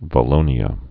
(və-lōnē-ə, -lōnyə) or val·lo·ne·a (-nē-ə)